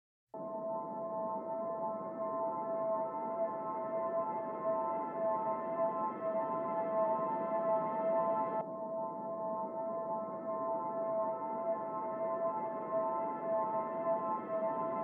Perkusja
Gitary
Instrumenty klawiszowe, bass, instr. perkusyjne, piano